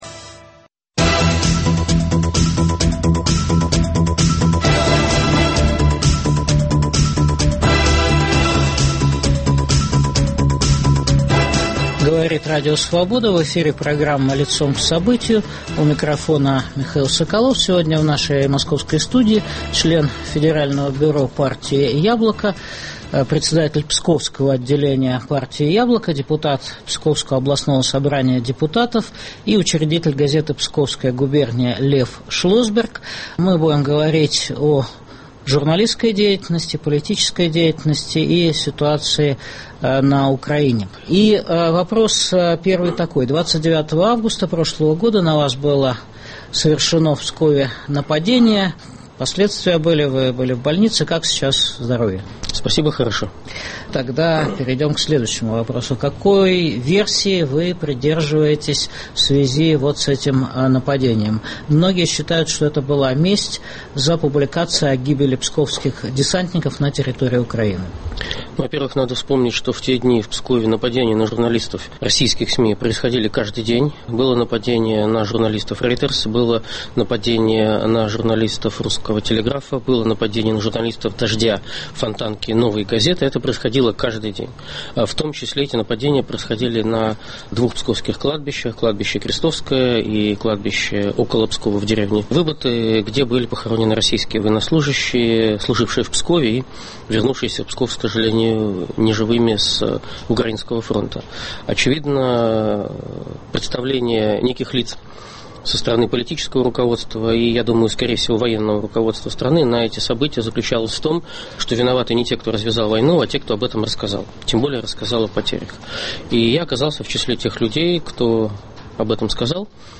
Беседуем с автором расследования о войне России в Украине, членом Бюро партии «Яблоко», депутатом Псковского областного собрания Львом Шлосбергом.